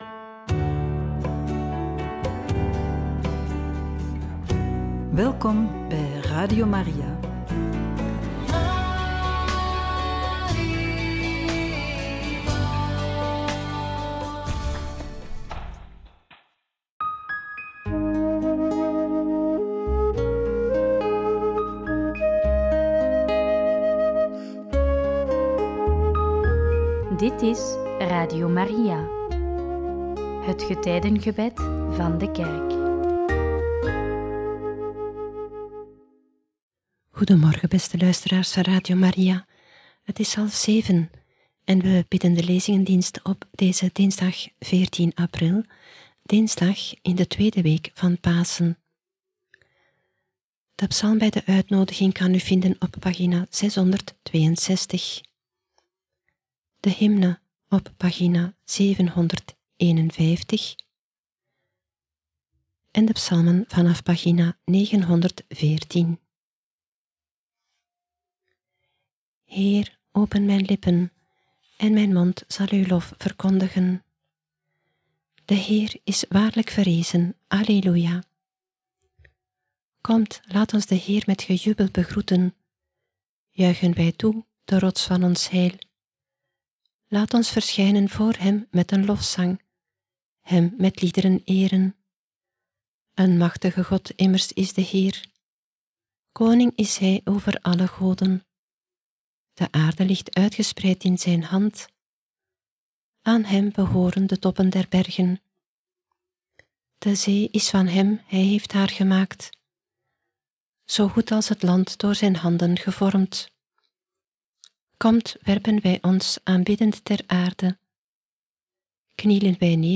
Lezingendienst